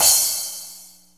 Crash5-44S.wav